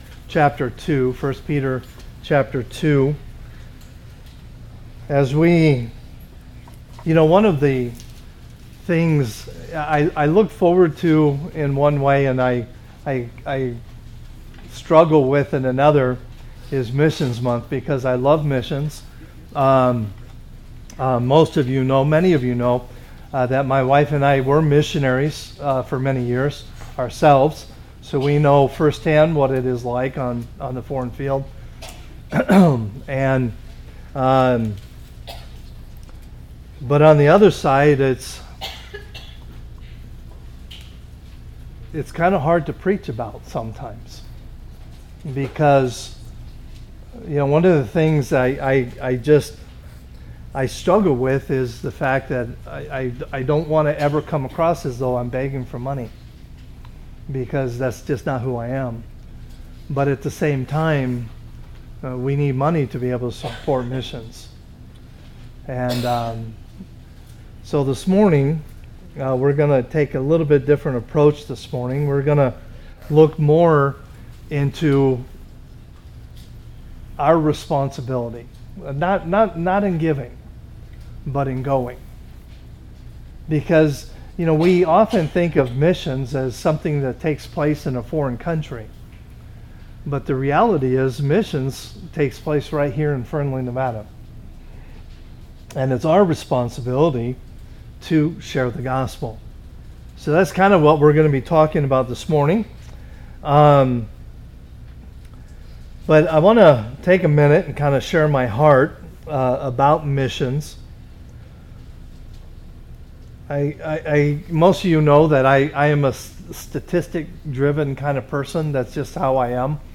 A message from the series "General Series."